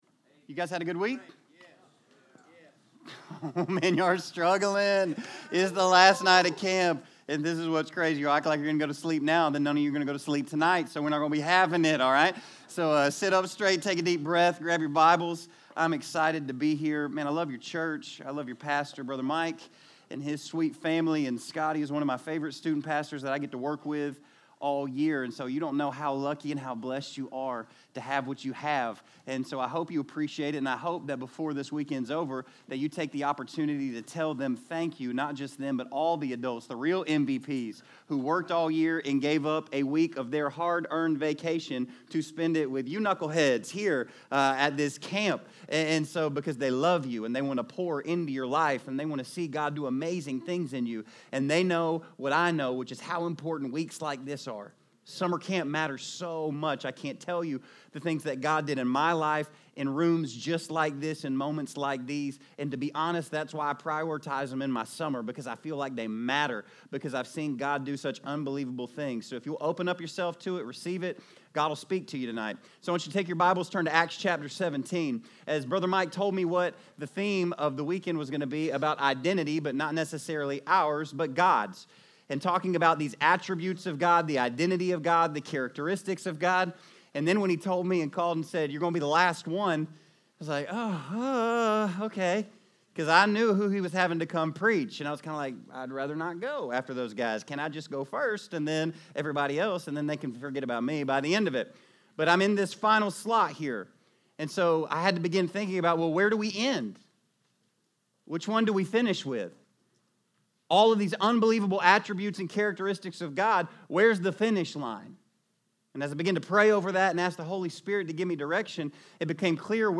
From the evening session of ESM Summer Camp on Thursday, June 27, 2019